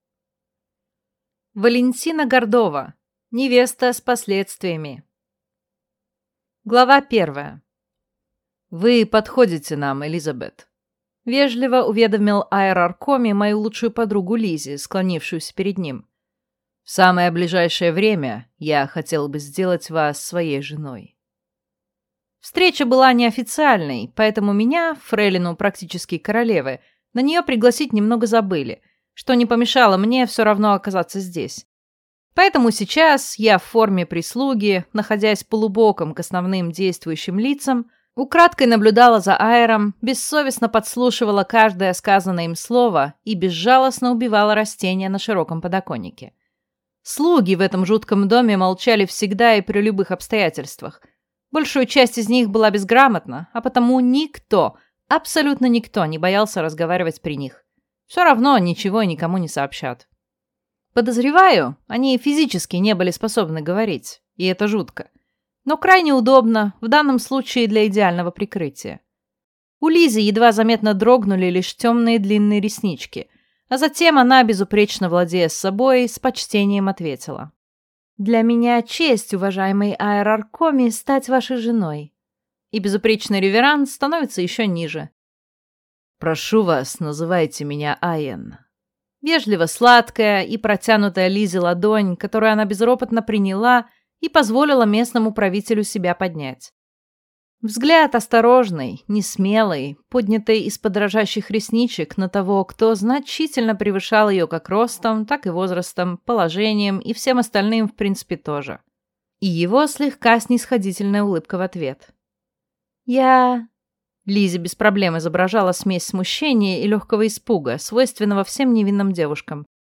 Аудиокнига Невеста с последствиями | Библиотека аудиокниг